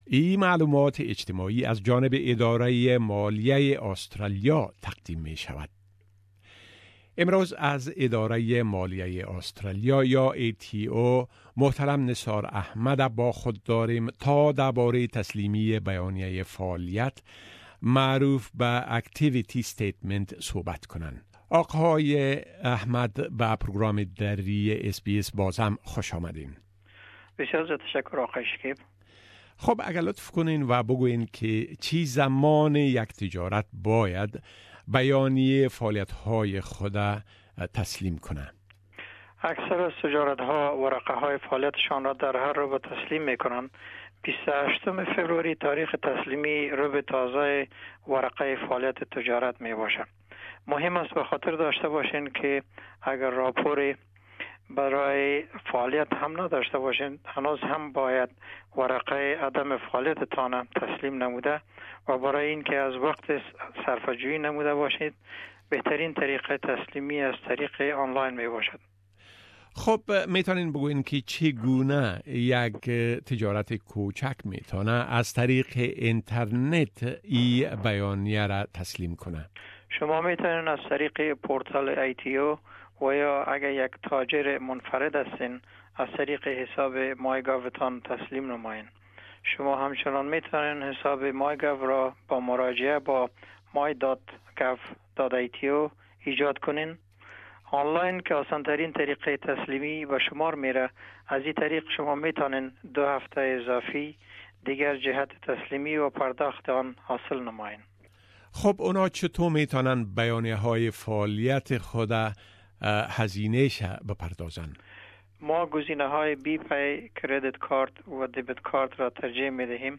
The following tax talk segment is a community service announcement from the Australian Taxation Office.